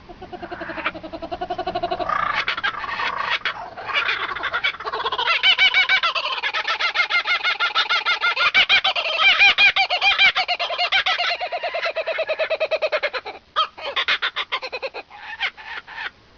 I was a bit startled however by a strange sound that was  emanating from behind me in the fuselage of GLM as we triumphantly joined cross-wind for landing!